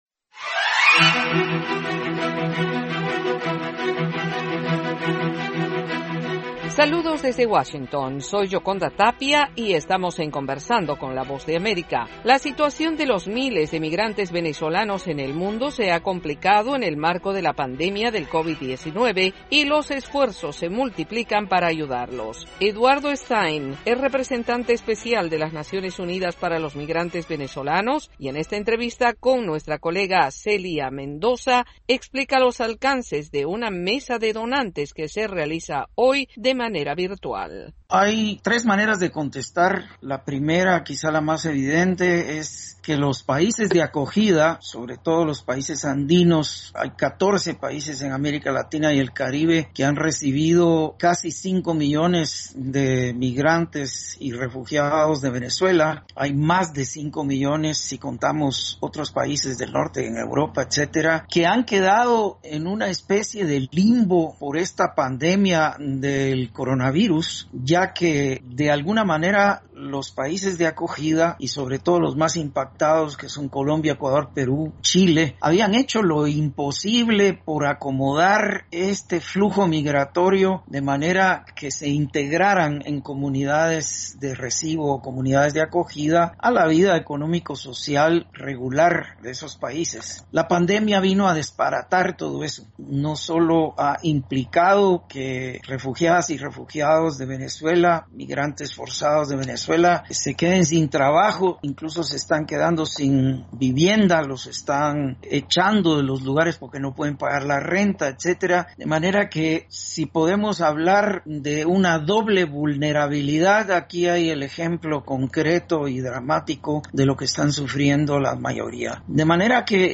Conversamos con Eduardo Stein, representante de la ONU para los migrantes venezolanos.